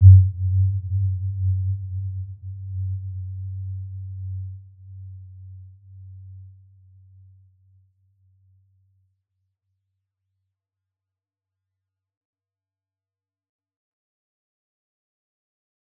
Warm-Bounce-G2-mf.wav